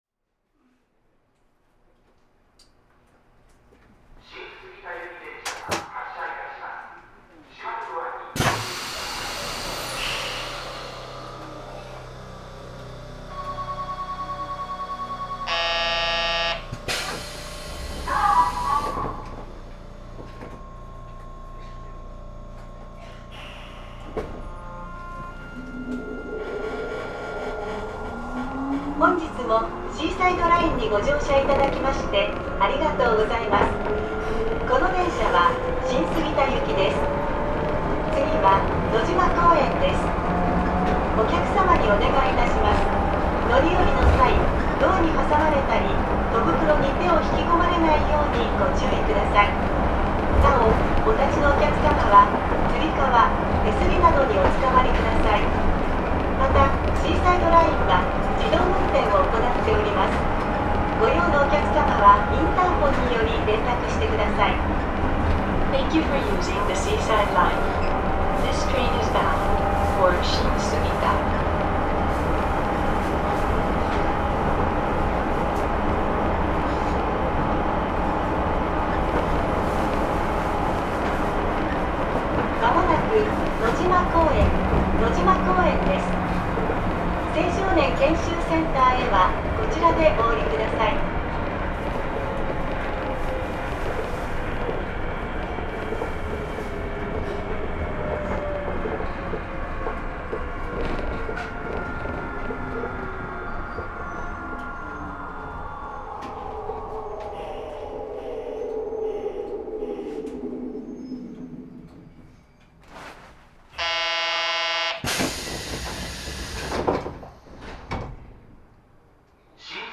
横浜シーサイドライン 1000形 ・ 走行音(異音車・全区間) (35.4MB*) 収録区間：金沢八景→新杉田 制御方式：高周波分巻チョッパ制御 開業と同時に登場した車両。